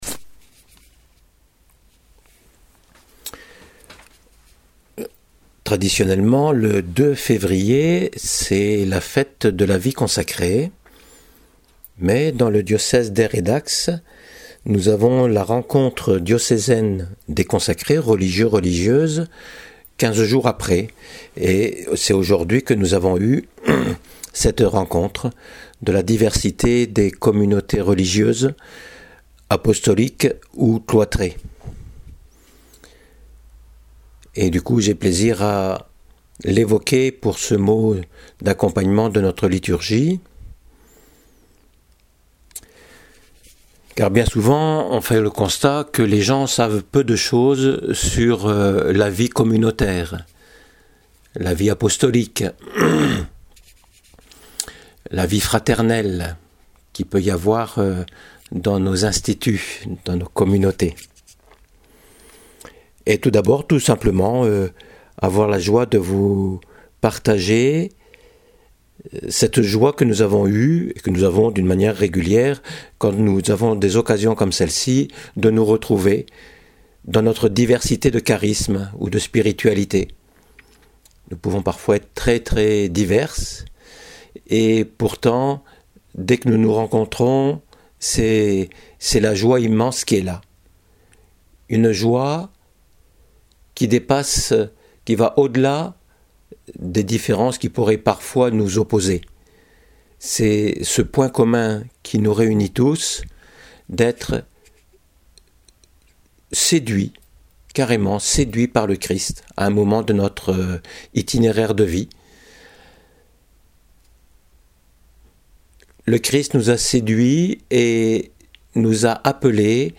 c’est la joie du témoignage en version audio => vie consacrée une joie immense